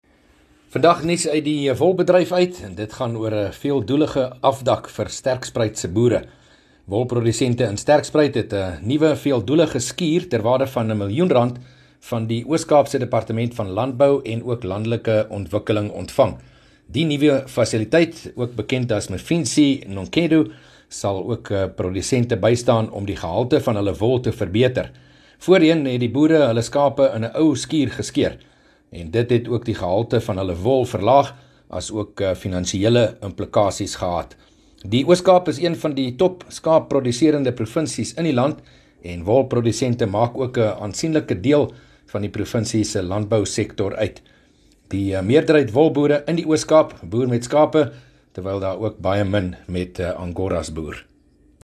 3 Jul PM berig oor opkomende boere in die Oos-Kaap wat hulp van die provinsiale regering ontvang het